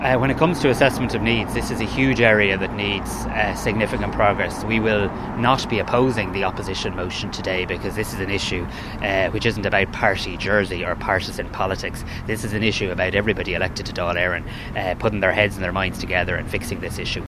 Simon Harris says the issue has to be resolved on all sides…………..